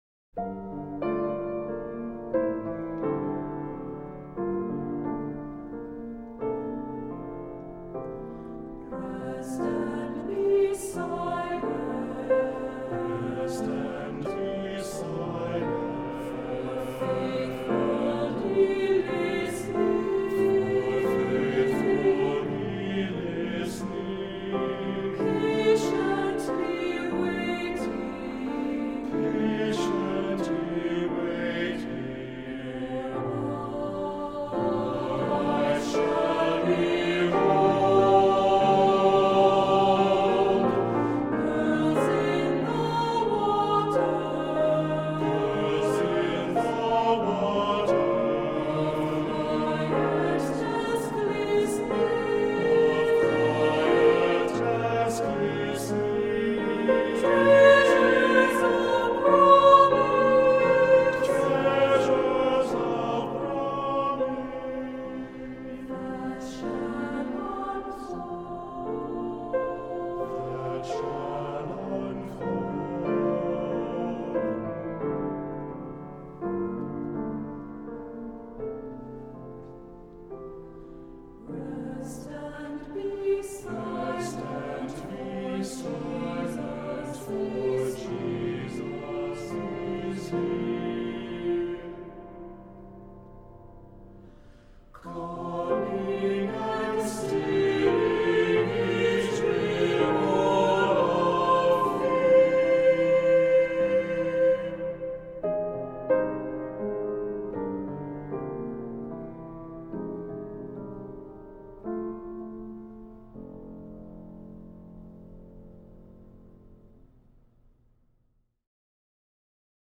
Voicing: Two-part equal; Two-part mixed